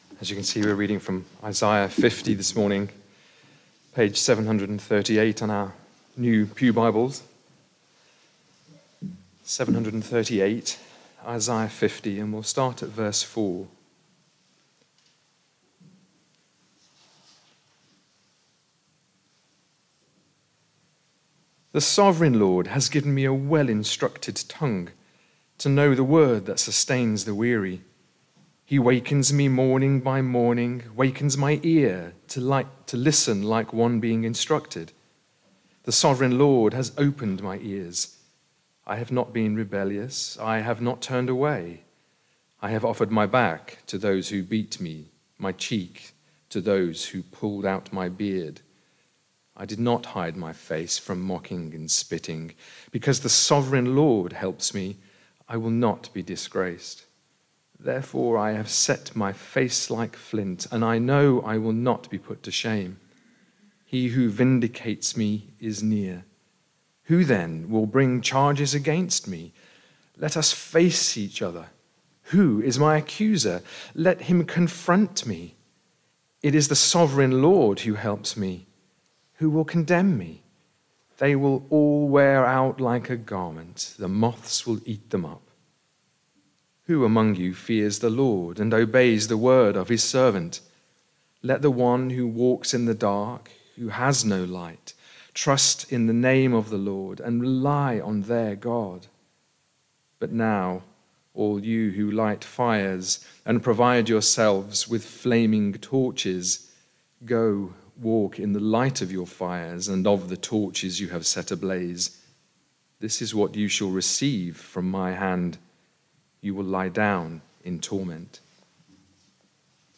Barkham Morning Service
Sermon